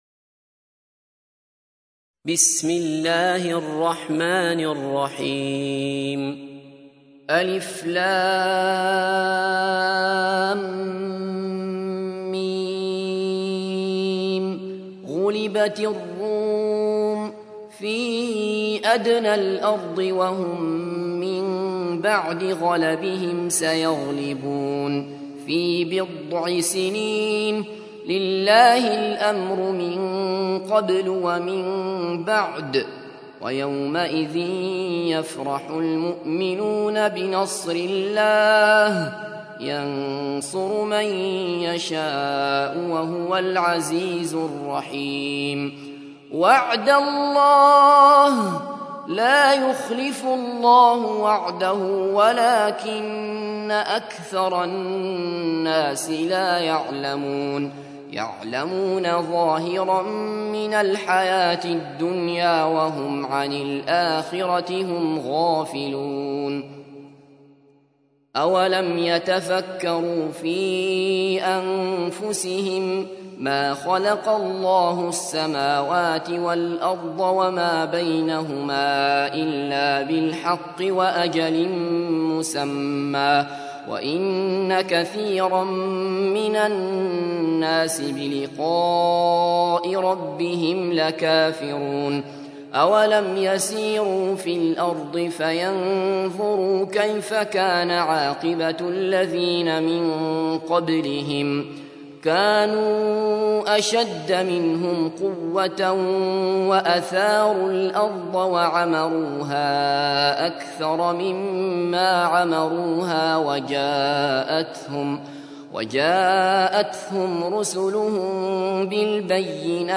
تحميل : 30. سورة الروم / القارئ عبد الله بصفر / القرآن الكريم / موقع يا حسين